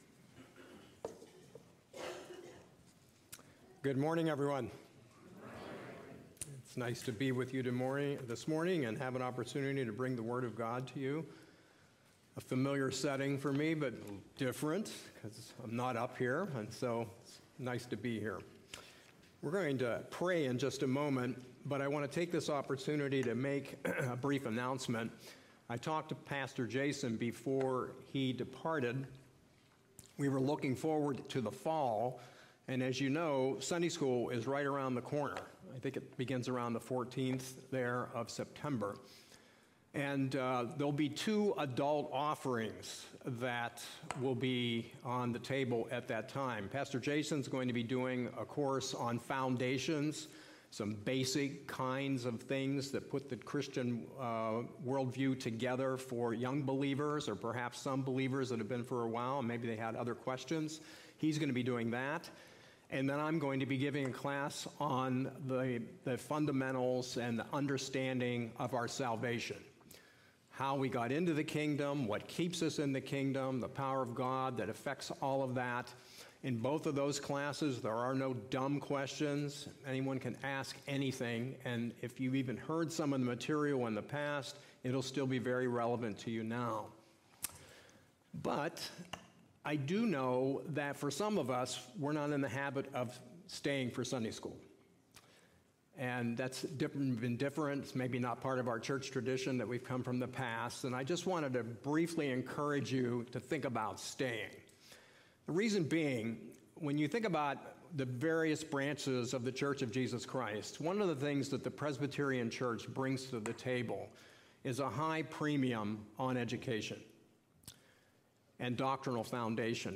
Sermons Passage: Daniel 2:1-17 Services: Sunday Morning Service Download Files Notes Previous Next